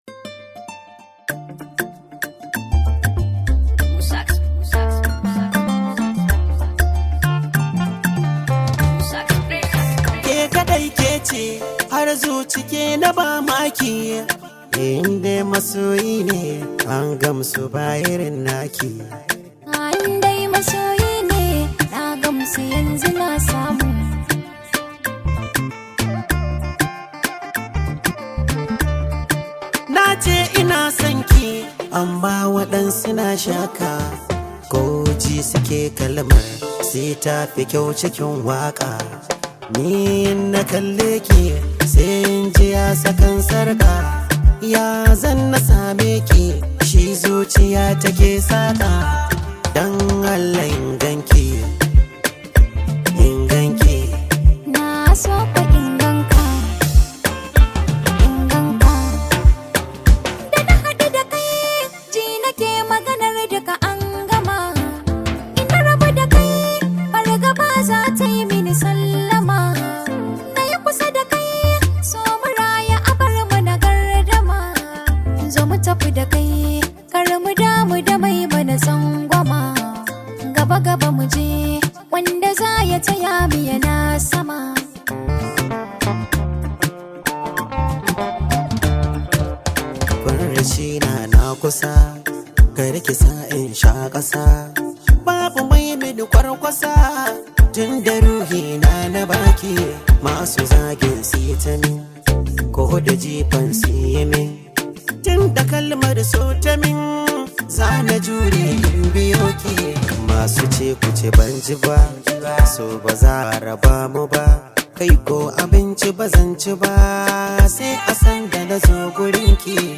hausa music track
Arewa rooted song